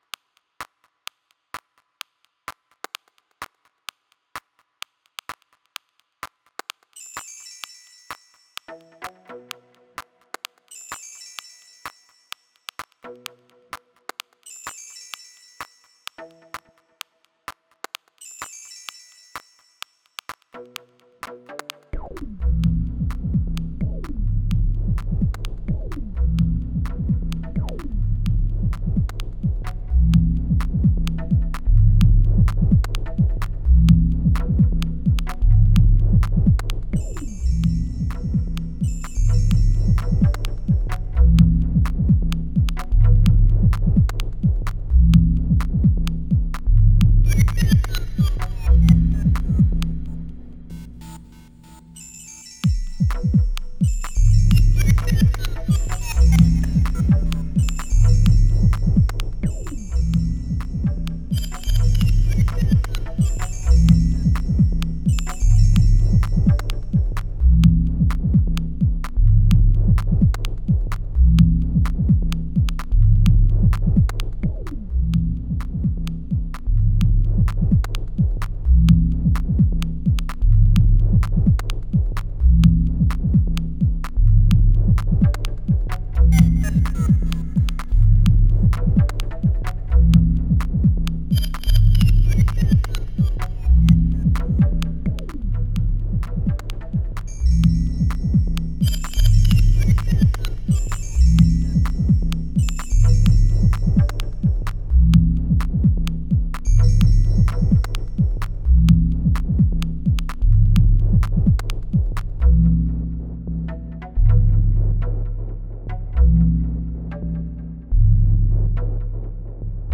Digitakt cowbell only jam!!